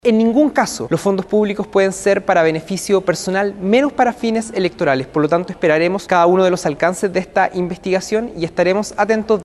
En tanto, desde el oficialismo, el diputado Juan Santana (PS) afirmó que esperarán cada uno de los alcances de la investigación contra el Gobierno Metropolitano, para así, evaluar las acciones a seguir a nivel parlamentario.